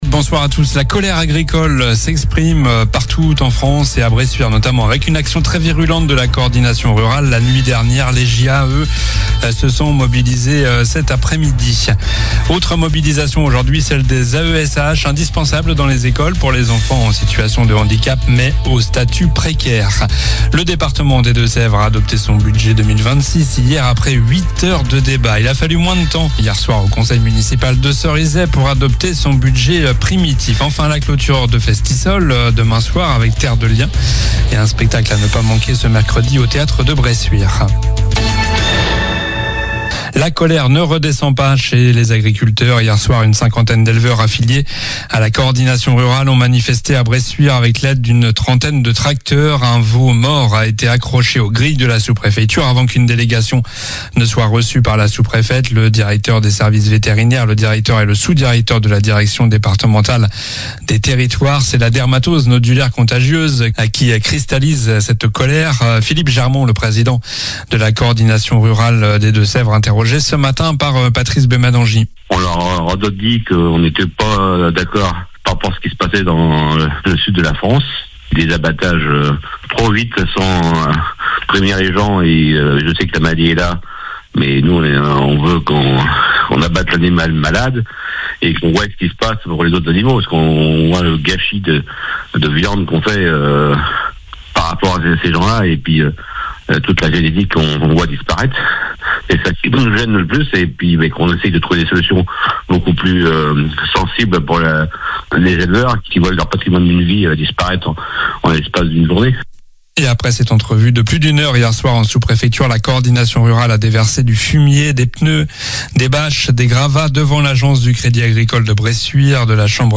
Journal du mardi 16 décembre (soir)